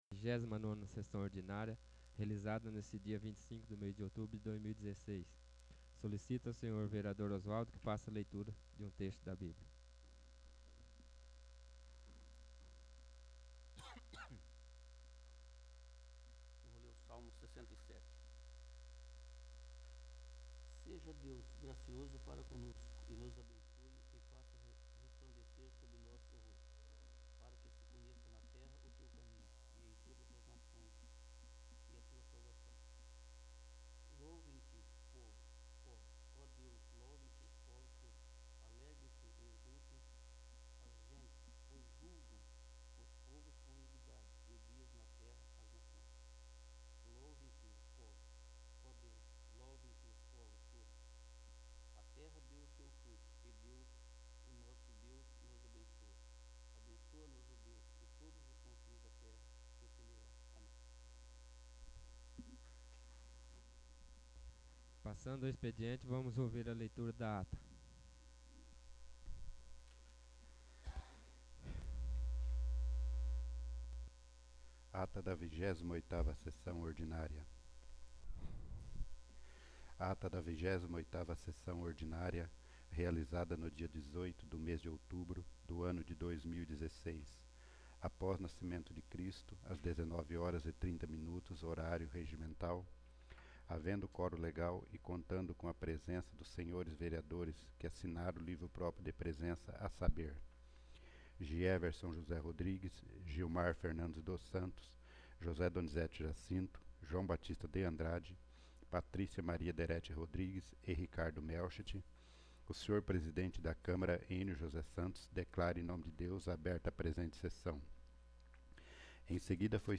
29º. Sessão Ordinária
29o-sessao-ordinaria